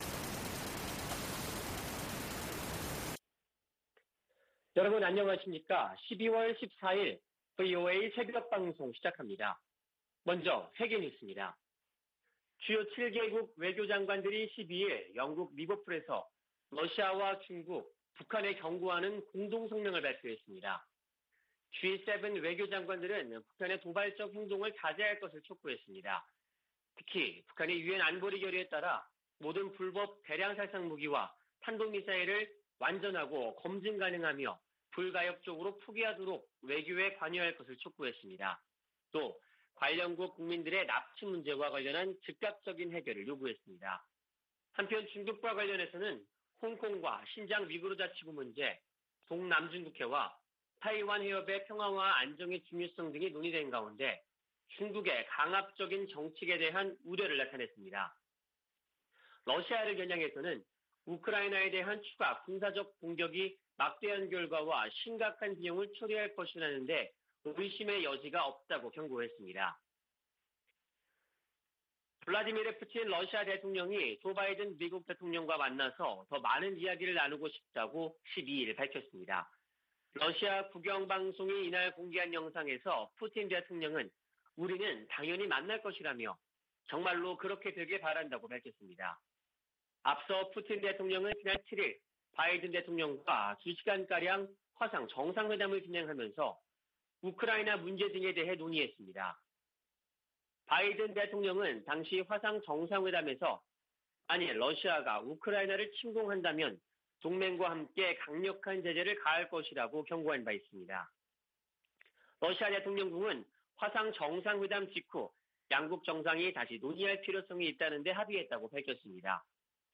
VOA 한국어 '출발 뉴스 쇼', 2021년 12월 14일 방송입니다. 미 재무부가 리영길 국방상 등 북한과 중국, 미얀마 등에서 심각한 인권 유린을 자행한 개인과 기관들에 경제 제재를 부과했습니다. 문재인 한국 대통령은 베이징 동계 올림픽 외교적 보이콧을 고려하지 않고 있다면서 중국의 건설적 역할의 필요성을 강조했습니다. 미국이 북한에 코로나 백신을 주겠다고 제안한다면 북한이 대화의 장으로 나올 수도 있을 것이라고 한국 국가정보원장이 말했습니다.